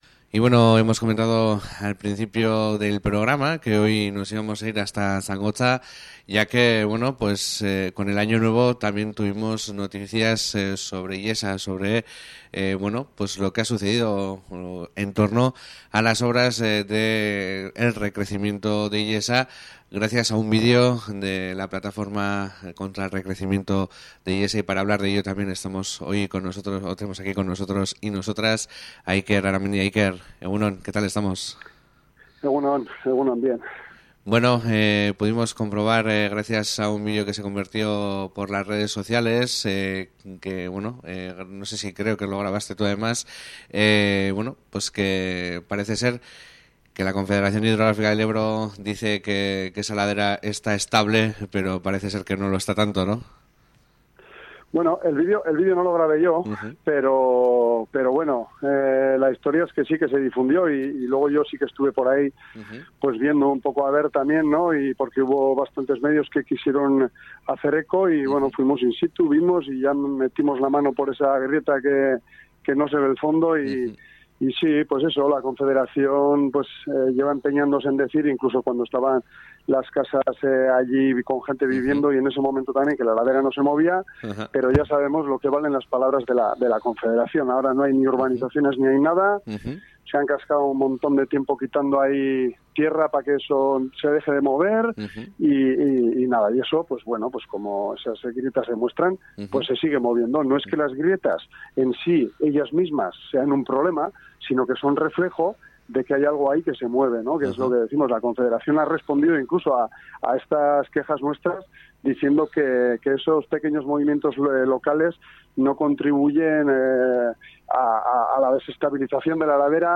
Egoeraz elekatzeko telefonoa hartu eta ESA + Ez Plataformako kidea den